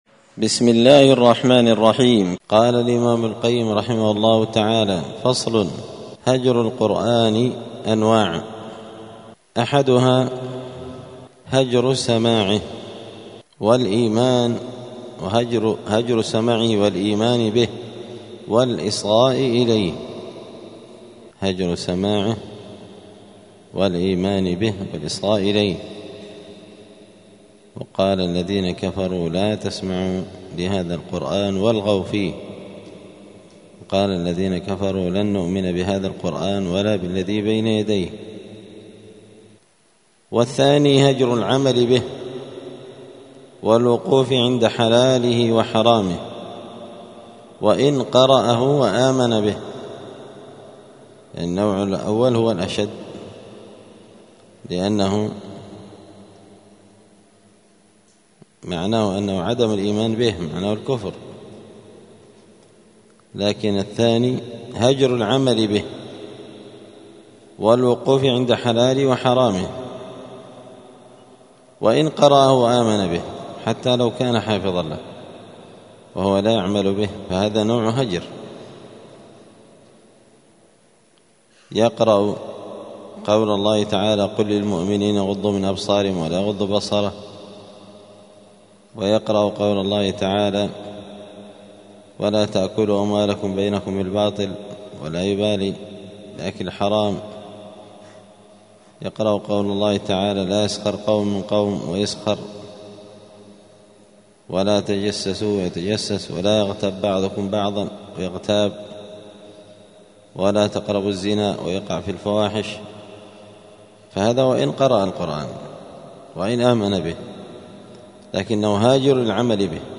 *الدرس الخامس والأربعون (45) {فصل: أنواع هجر القرآن}*